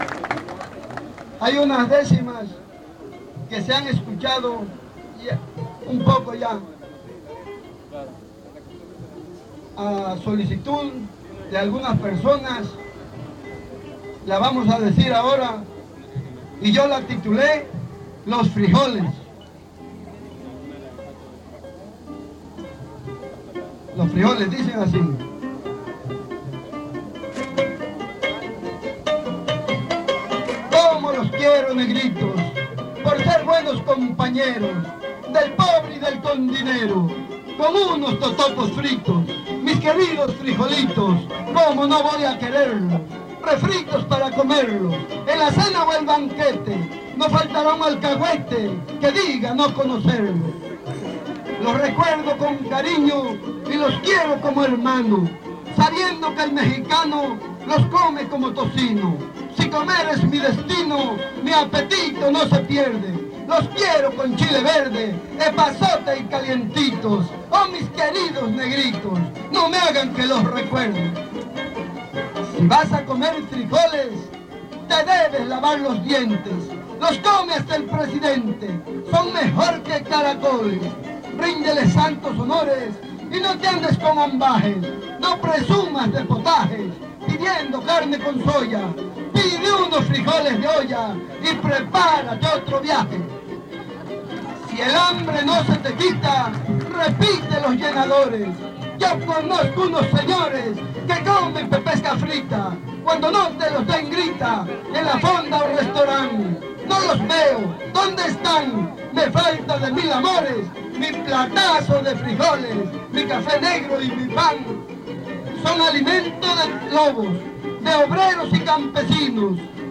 Encuentro de son y huapango